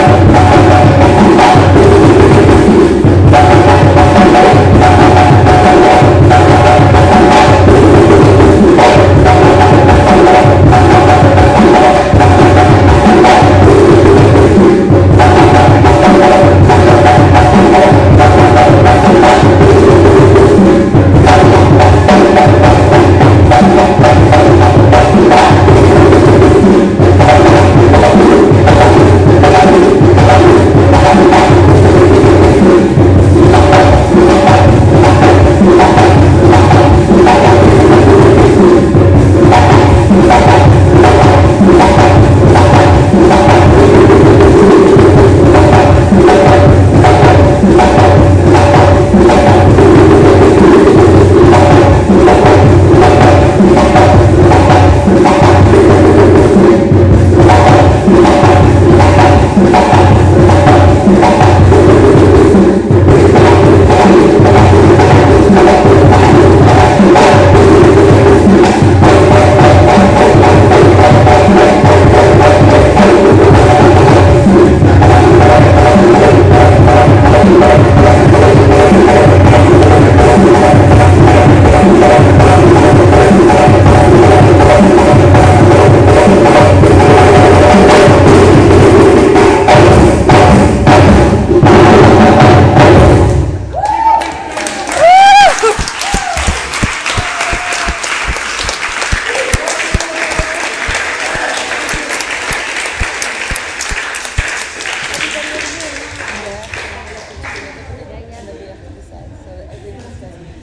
More Drumming From Ace Dance Auditions
17557-more-drumming-from-ace-dance-auditions.mp3